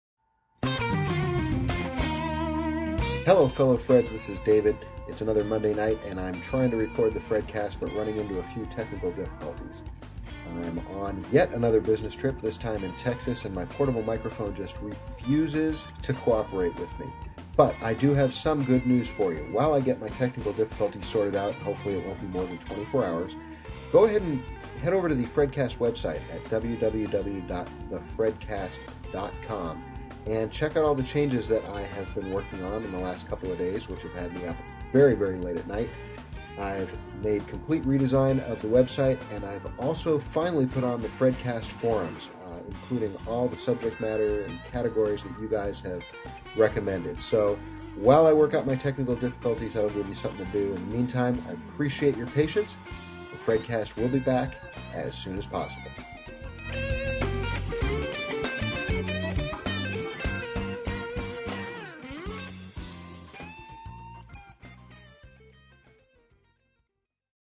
I’m on yet another business trip (blech!) and of course my portable microphone decides not to work.